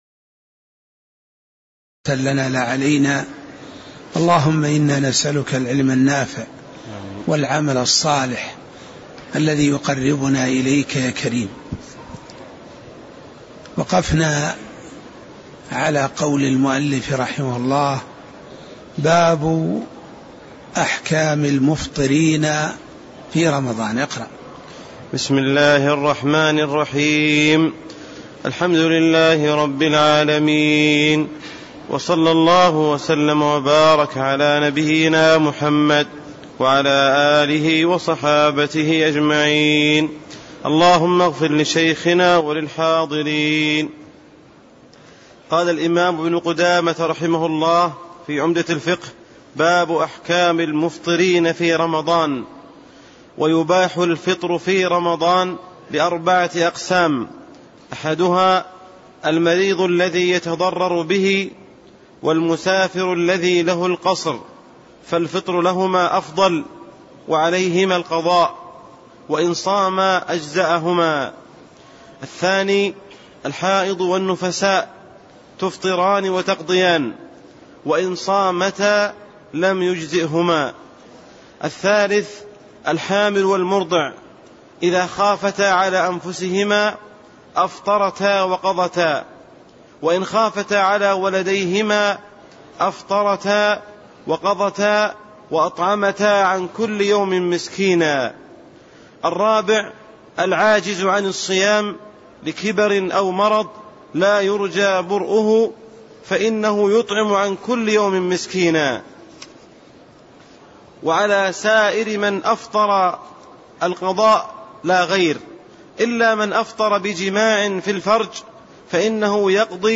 تاريخ النشر ٦ رمضان ١٤٣٤ هـ المكان: المسجد النبوي الشيخ